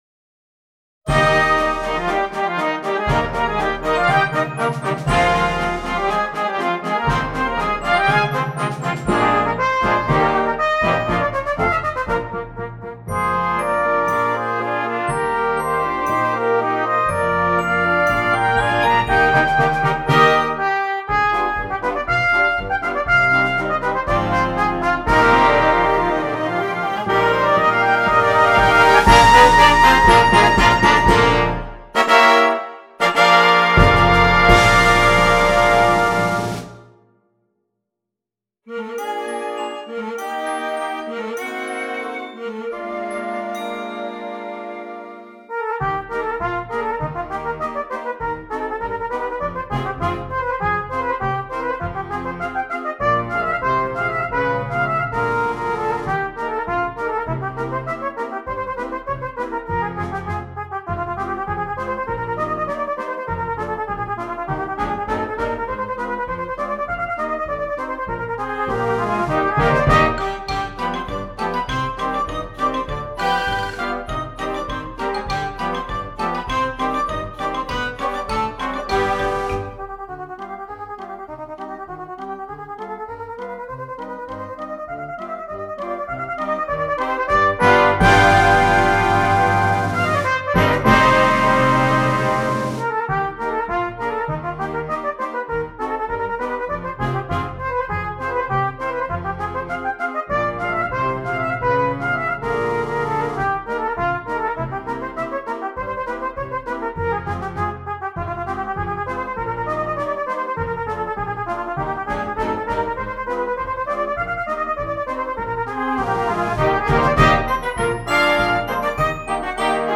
Solo Cornet and Concert Band
This fun light piece is a great showpiece for the cornet.